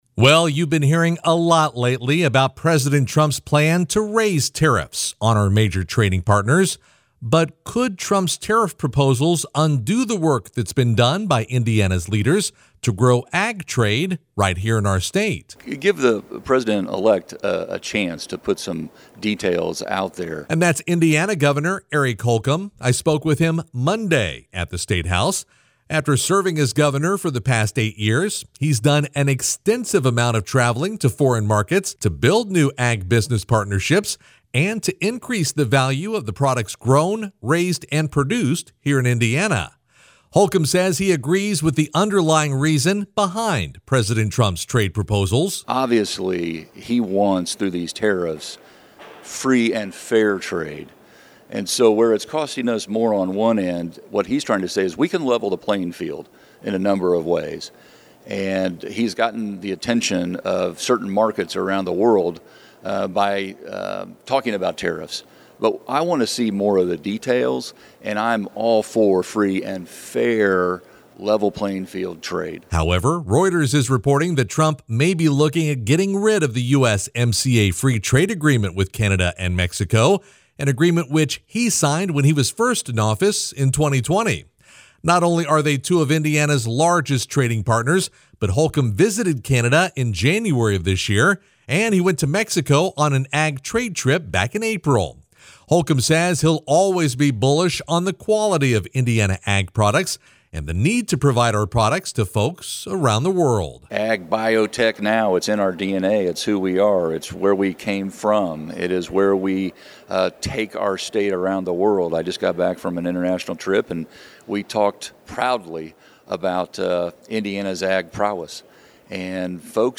CLICK BELOW to hear Hoosier Ag Today’s radio news report, as Gov. Eric Holcomb addresses President Trump’s tariff proposals.